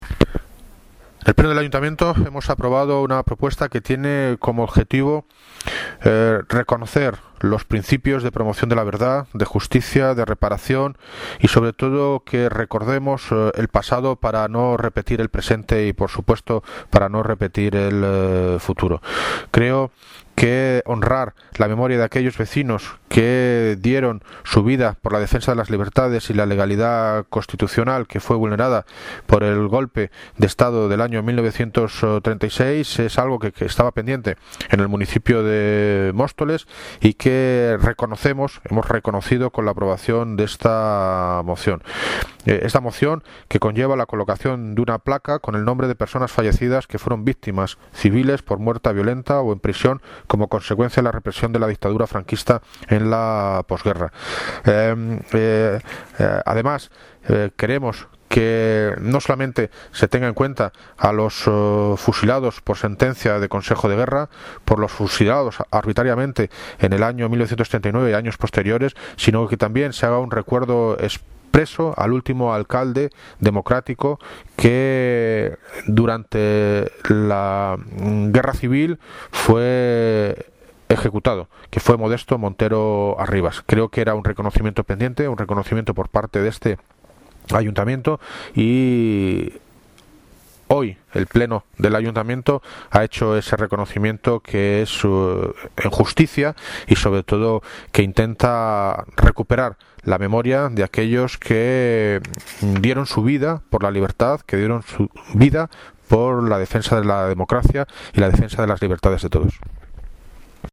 Audio - David Lucas (Alcalde de Móstoles) Sobre Represaliados